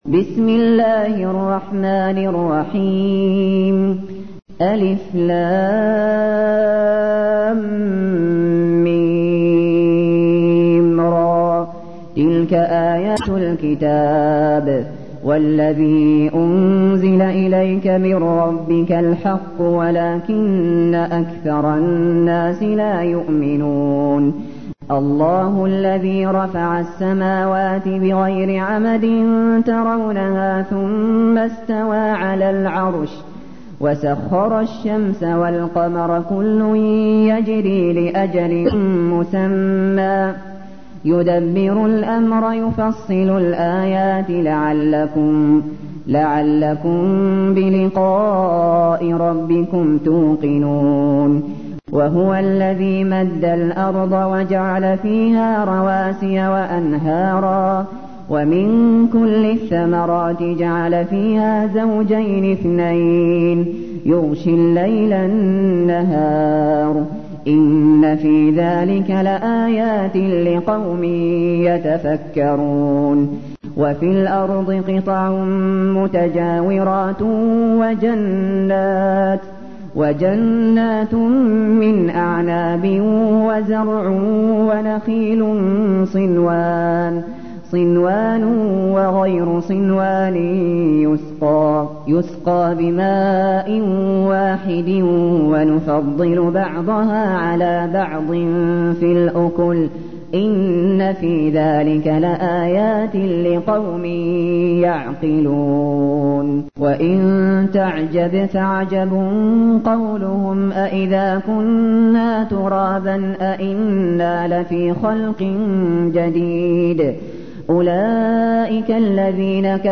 تحميل : 13. سورة الرعد / القارئ الشاطري / القرآن الكريم / موقع يا حسين